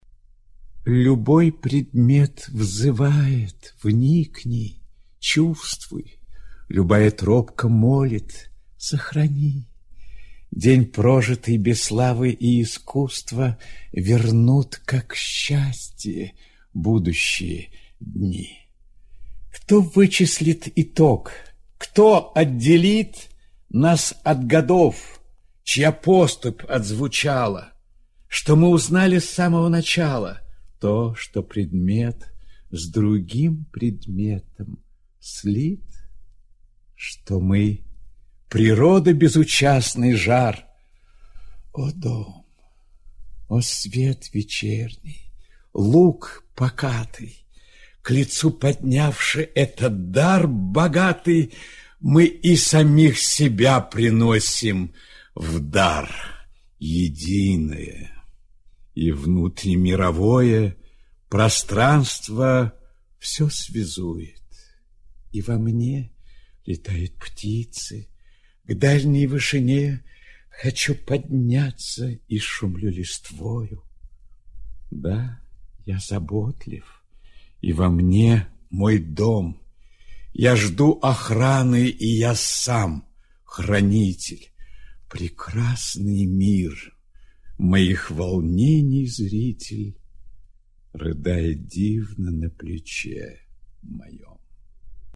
rilke-rajner-mariya-lyuboj-predmet-vzyvaet-chit-i-m-smoktunovskij